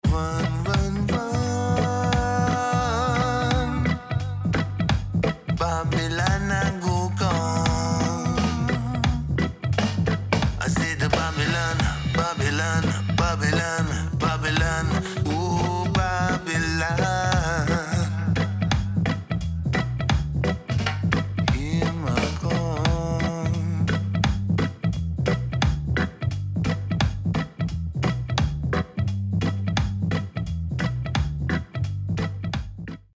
Caution: Loud
Mixed & Mastered
Mixed (No Mastering)